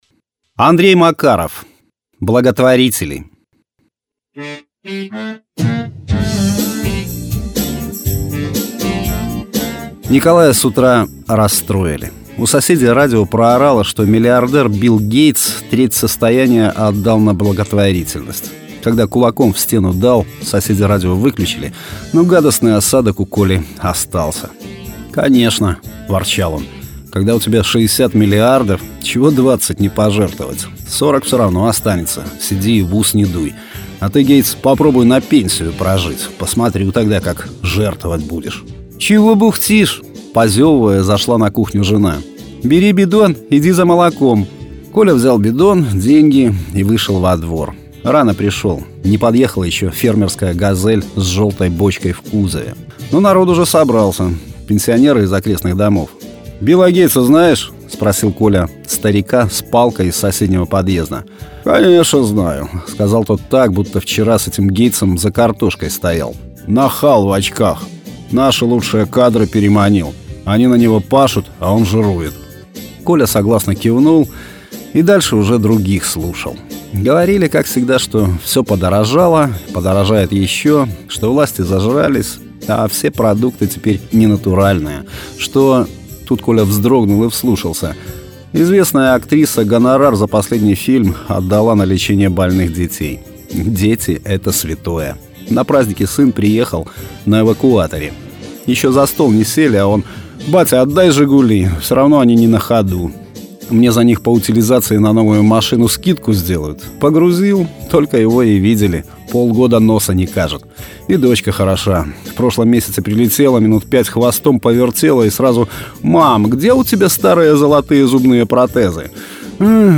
Аудиорассказ: Андрей Макаров. Благотворители
Жанр: Современная короткая проза